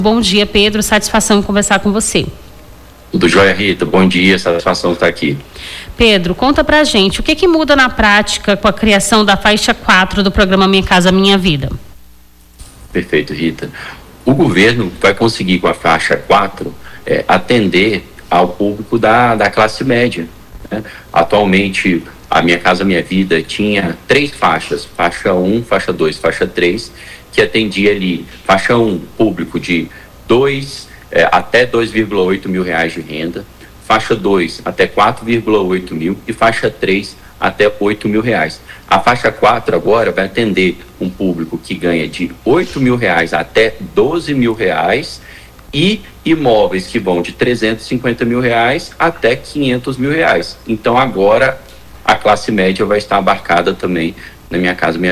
AO VIVO: Confira a Programação
Nome do Artista - CENSURA - ENTREVISTA (PROGRAMA MINHA CASA MINHA VIDA) 10-04-25.mp3